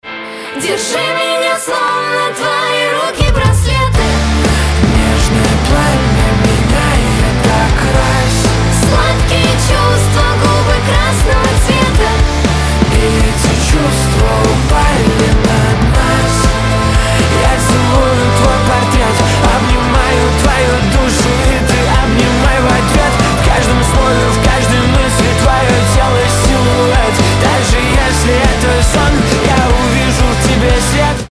Танцевальные рингтоны / Романтические рингтоны